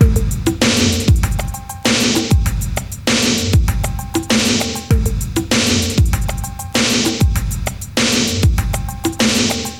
• 98 Bpm Drum Groove A# Key.wav
Free drum groove - kick tuned to the A# note. Loudest frequency: 2184Hz
98-bpm-drum-groove-a-sharp-key-MRD.wav